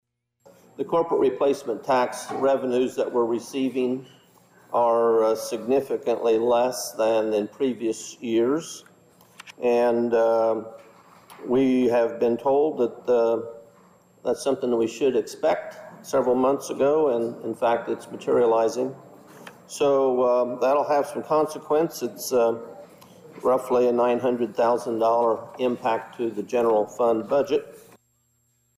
That was Finance Committee chairman Steve Miller delivering the message to the county board.  Miller added this is only the sixth month of the fiscal year, so it’s something that needs to be monitored.